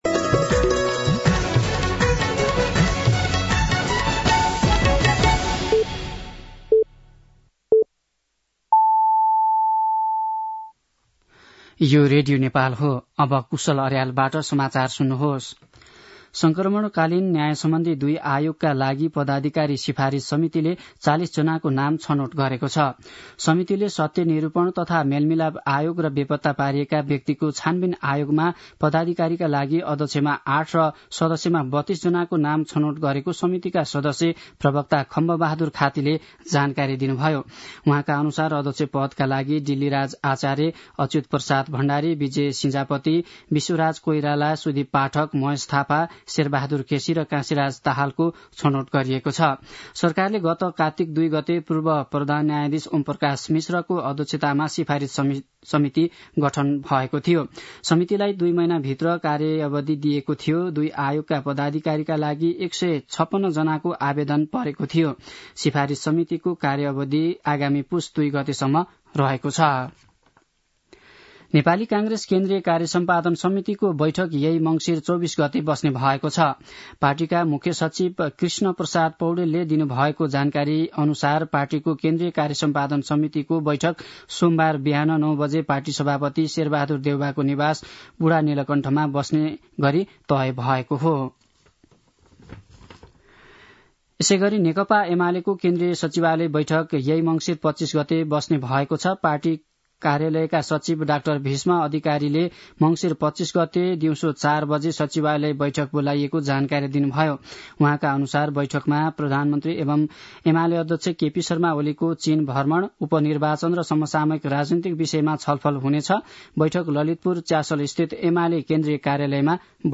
दिउँसो १ बजेको नेपाली समाचार : २३ मंसिर , २०८१
1-pm-Nepali-News.mp3